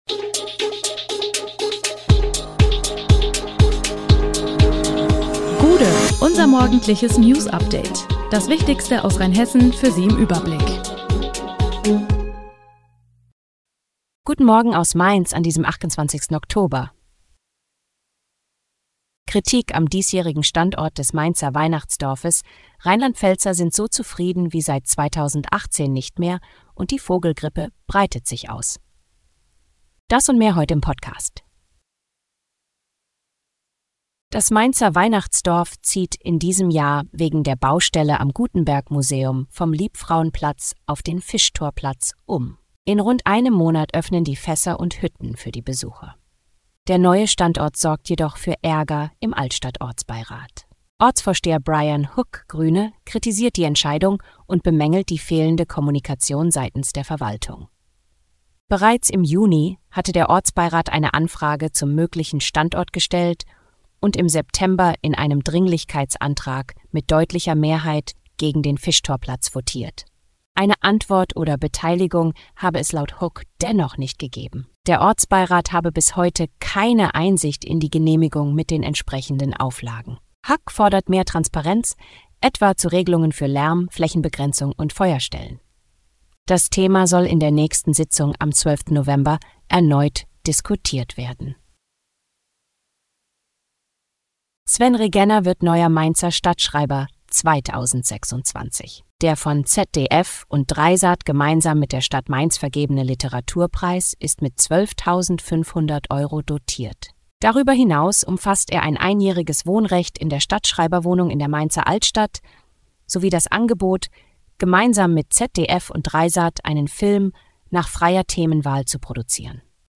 Der Podcast am Morgen für die Region
Nachrichten